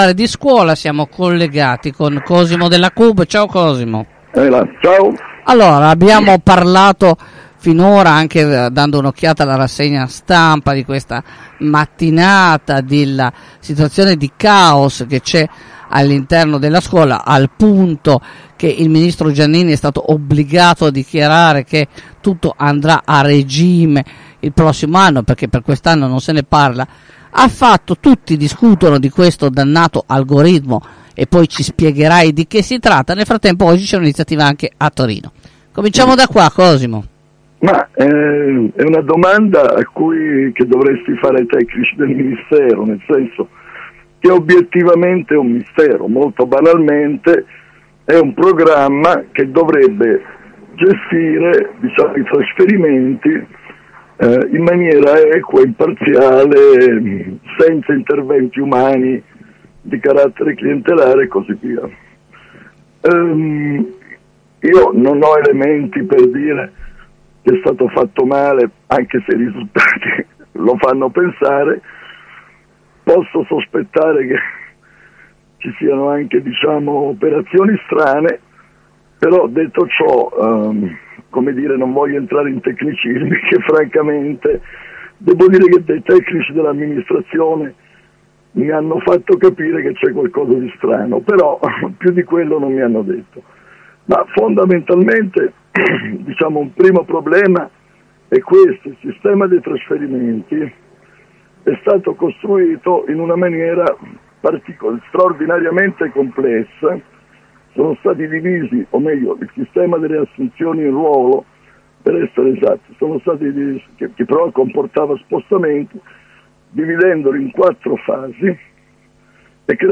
Ascolta la diretta: